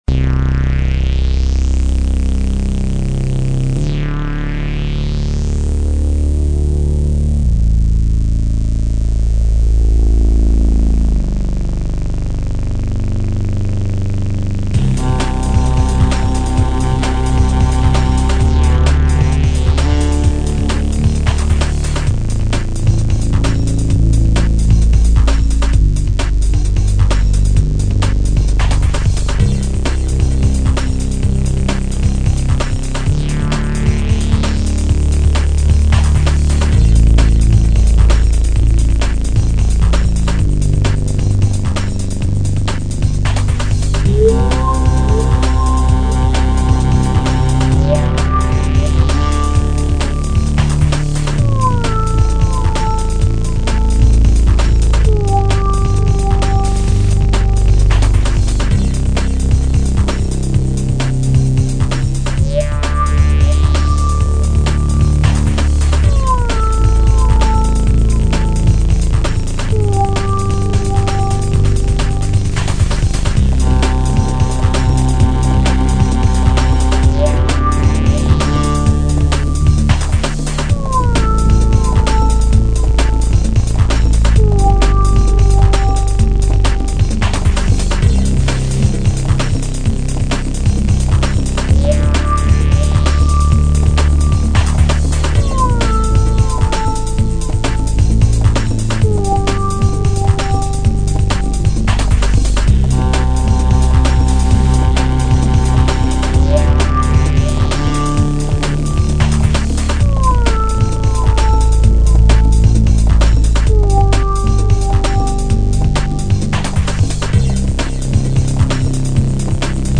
yoga inspired techno\house
House
Techno